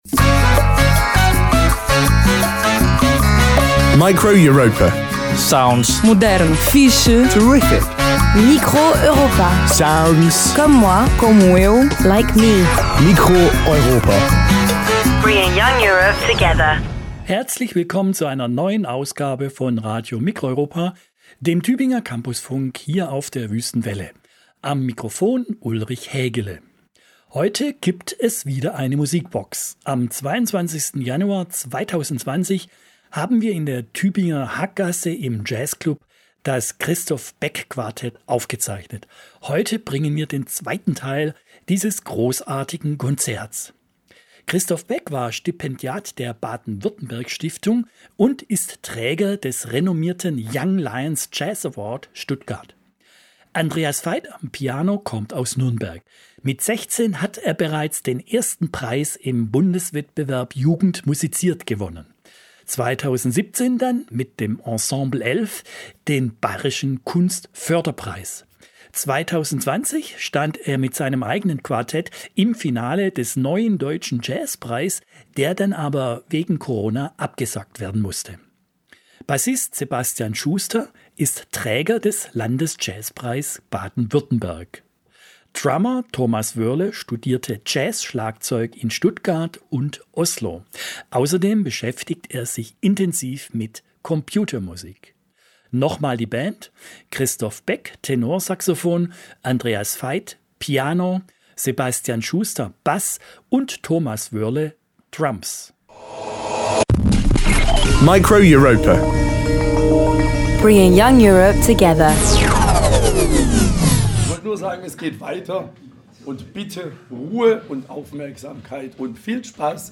Piano
live im Tübinger Jazzclub
Tenorsaxofon
Bass
Drums
Form: Live-Aufzeichnung, geschnitten